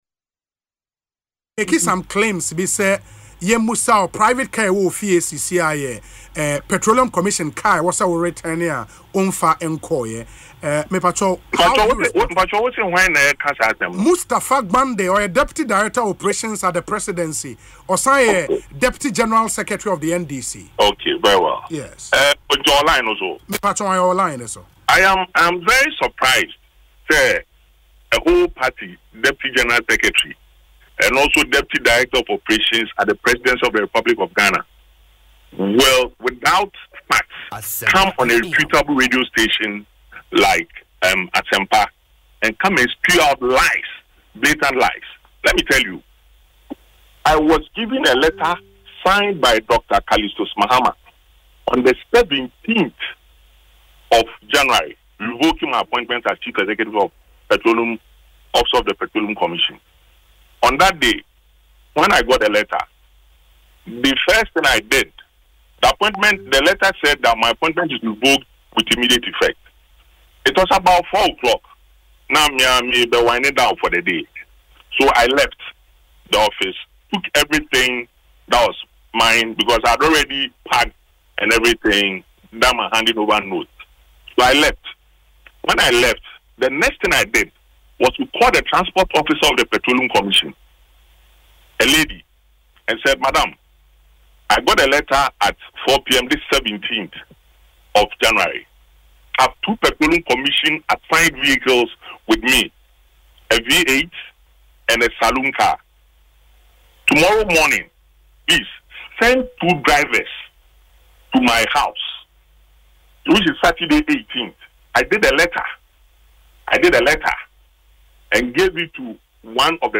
He expressed shock that a Deputy Director of Operations would come on live radio to make such unverified claims.
In a sharp rebuttal, Mr. Faibille fumed, “We’re not brothers. Don’t call me your brother; we’re not from the same region. Don’t make that mistake,” effectively silencing Mustapha Gbande.